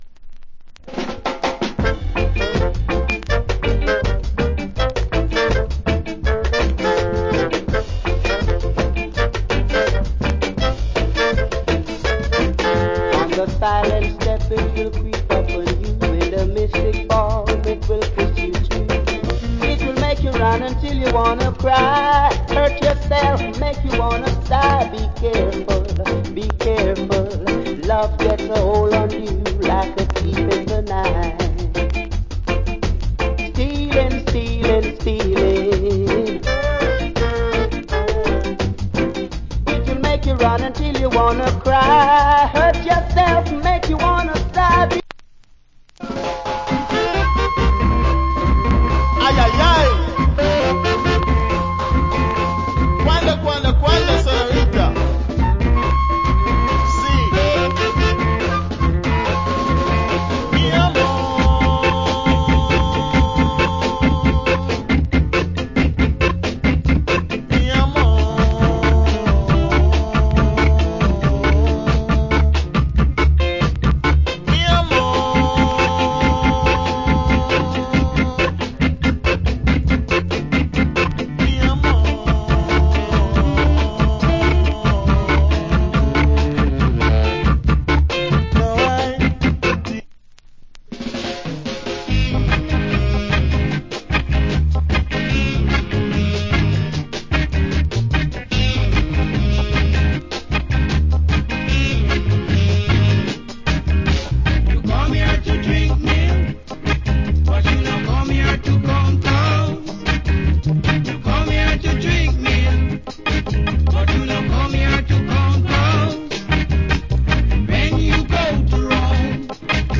Great Early Reggae Compilation Album.
60's